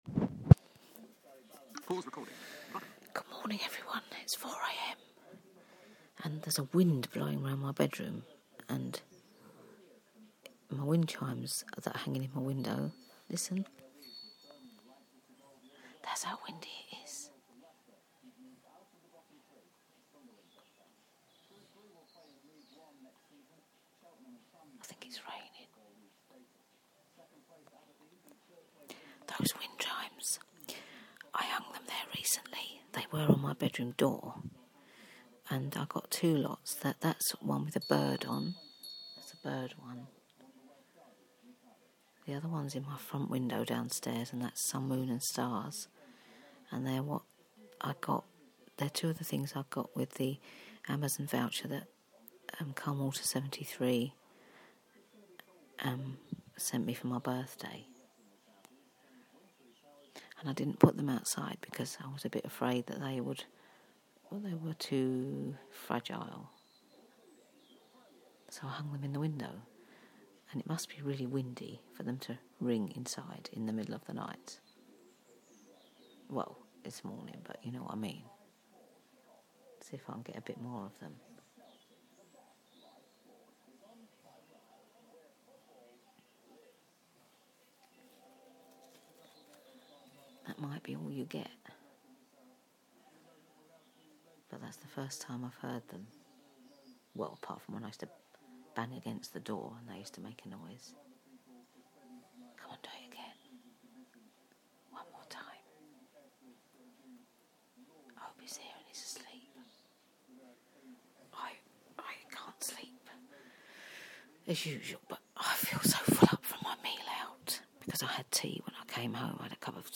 Wind chimes in the bedroom